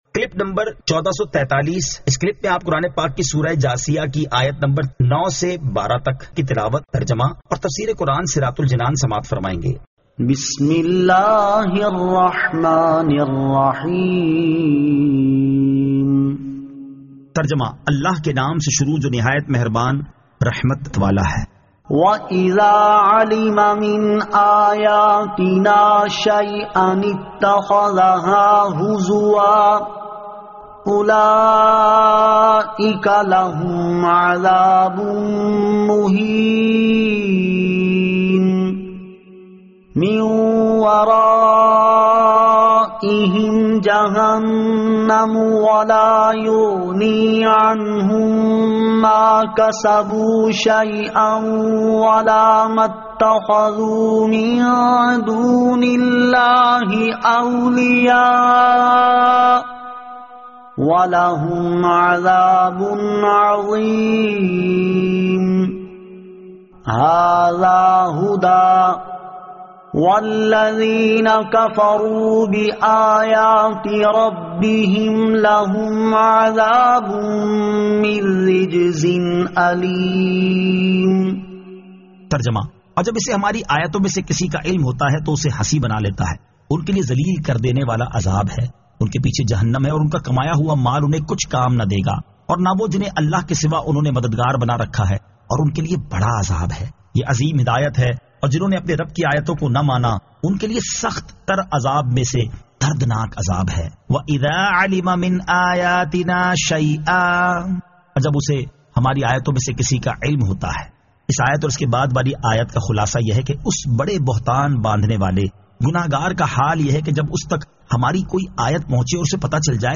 Surah Al-Jathiyah 09 To 12 Tilawat , Tarjama , Tafseer